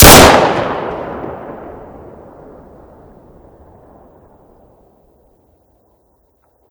sounds / eft_wp / colt1911 / shoot.ogg
shoot.ogg